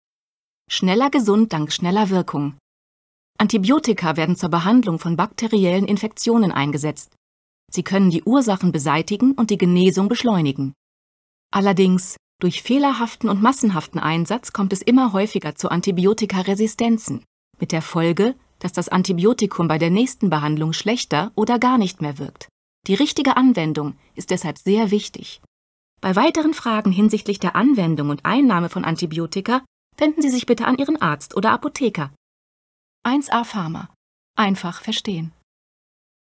Eine professionelle Sprecherin mit angenehmem Timbre und authentischem Klang in der Stimme.
Sprechprobe: Werbung (Muttersprache):